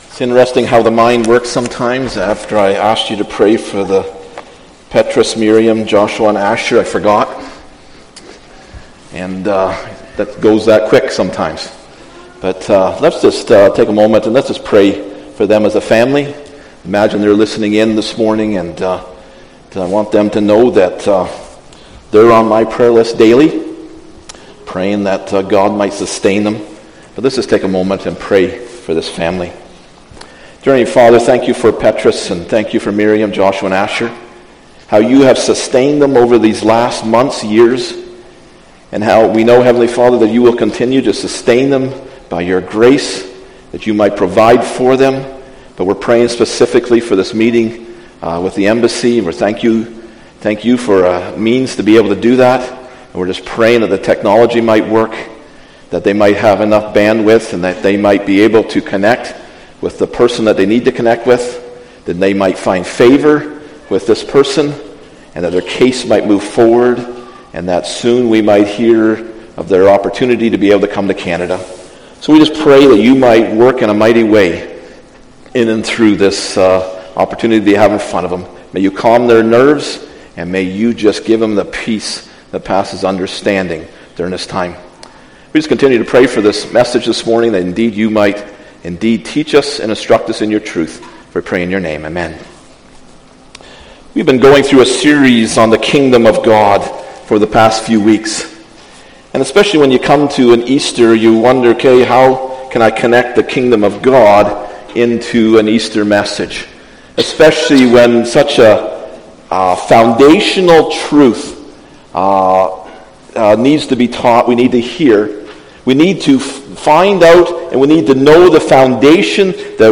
April 17th Sermon